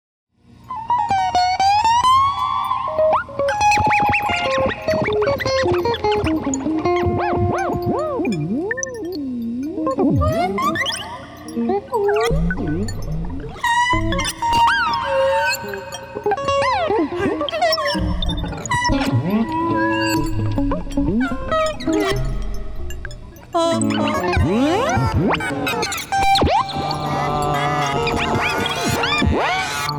Adventurous Electronic Excursions
Voice with Live Processing
Guitar and SuperCollider